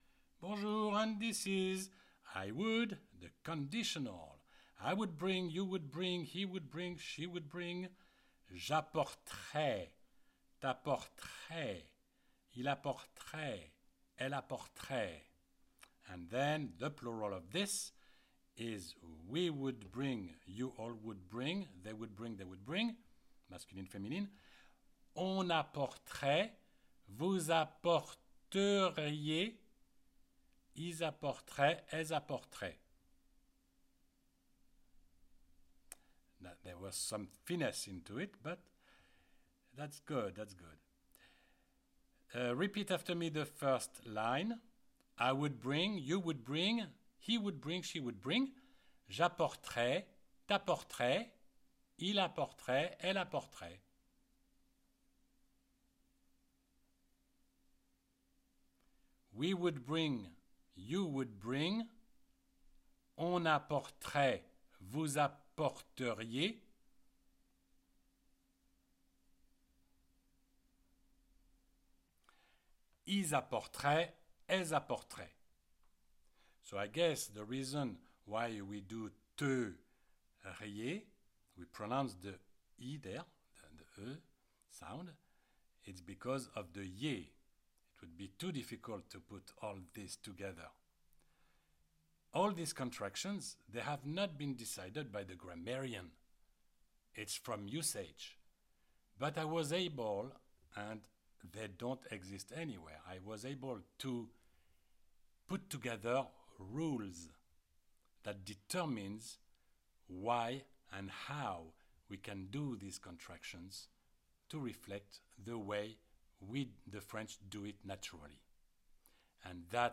CLICK ON THE PLAY BUTTON BELOW TO PRACTICE ‘APPORTER’, ‘TO BRING’, IN THE CONDITIONAL TENSE Just the sight of the classic apporter in french makes it difficult to learn the French conjugation .
You read and you repeat with the audio.